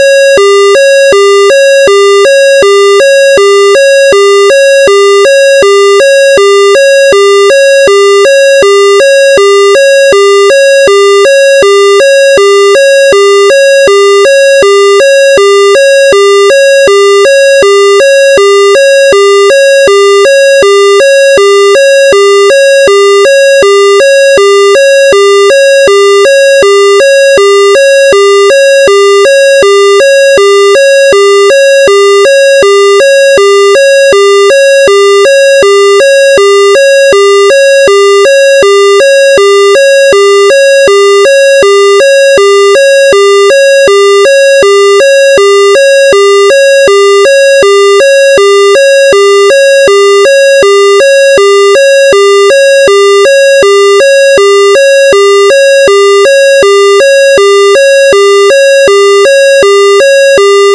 Dukane Hi-Lo Tone: